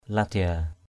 ladia.mp3